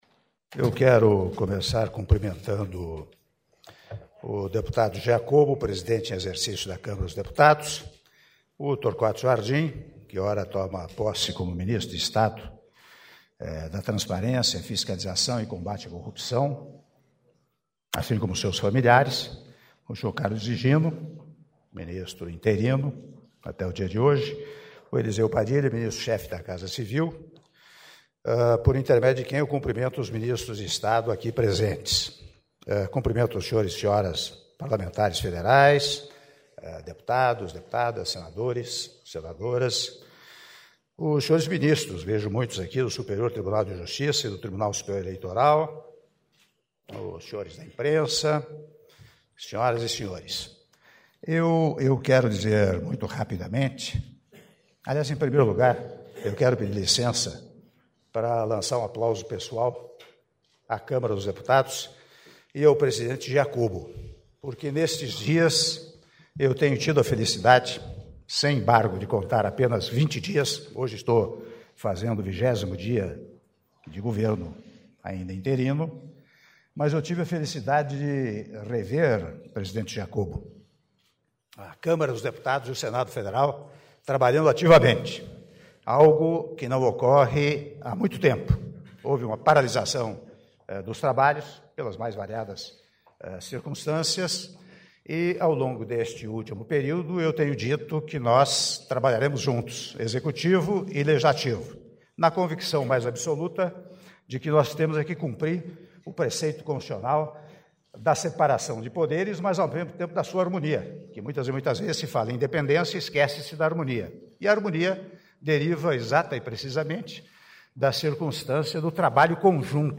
Áudio do discurso do Presidente da República interino, Michel Temer, na cerimônia de posse do ministro da Transparência, Torquato Jardim (07min44s)